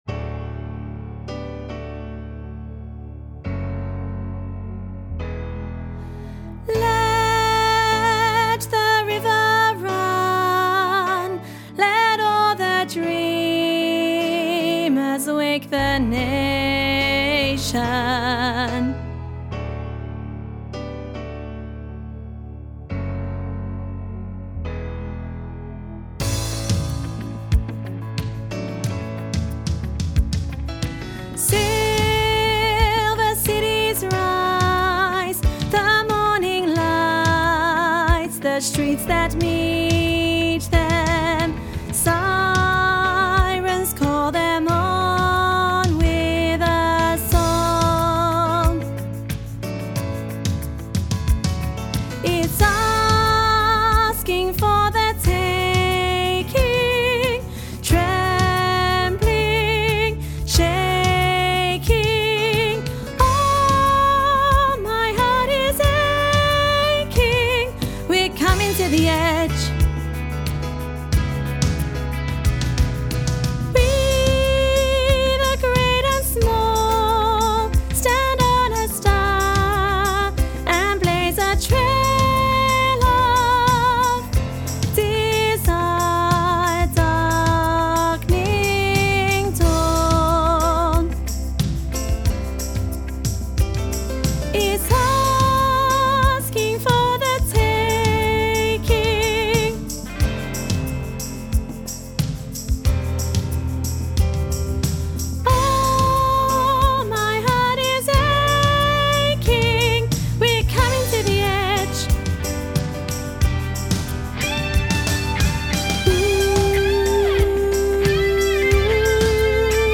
let-the-river-run-soprano.mp3